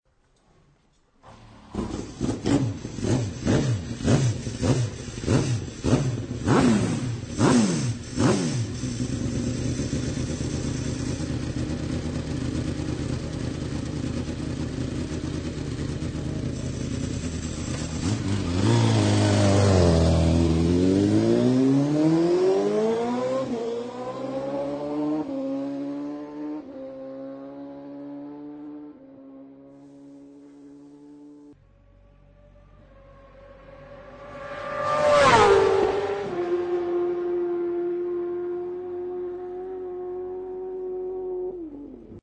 Sons Motos
Pilote : Olivier Jacque  (Champion du monde 250 cc 2000,Fra)